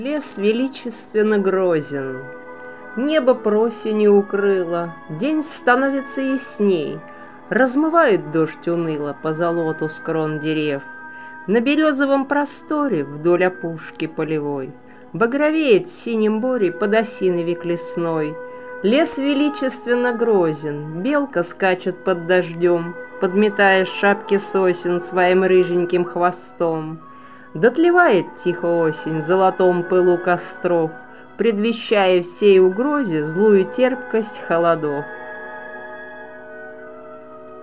Музыка классики Озвучка автора